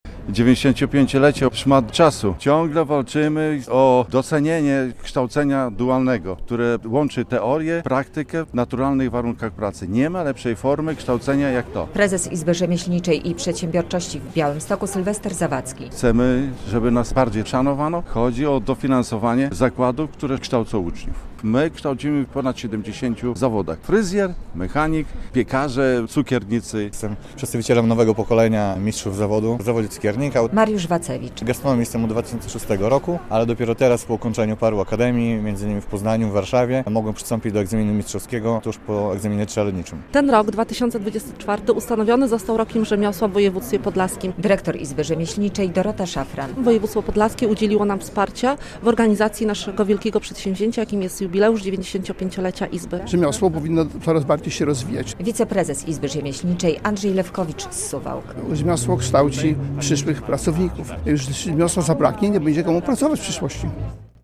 Było jubileuszowe nabożeństwo w białostockiej archikatedrze, a potem uroczysta gala.